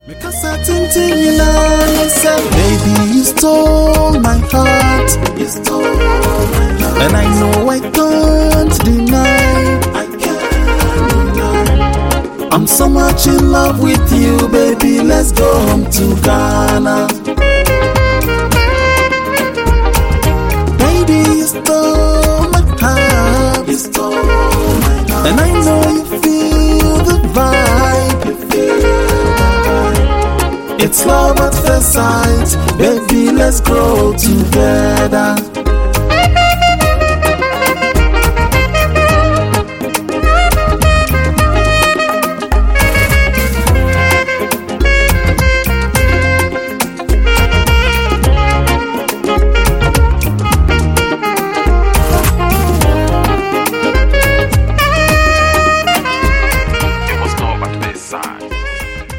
Highlife Music